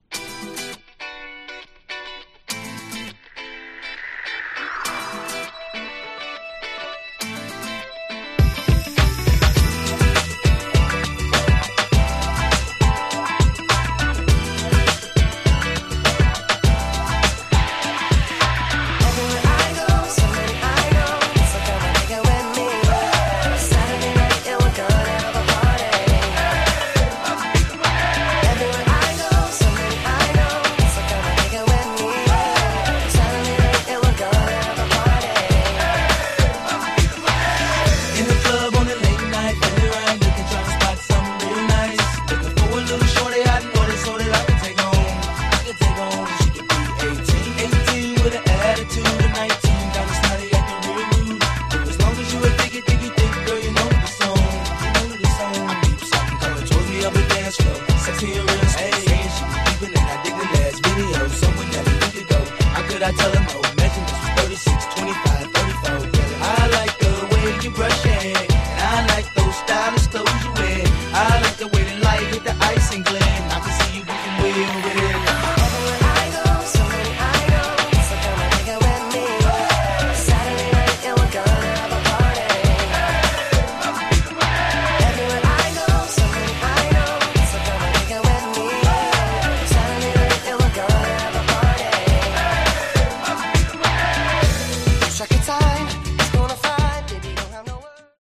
ほとんど同じトラックにあのサビ…(笑)
キャッチー系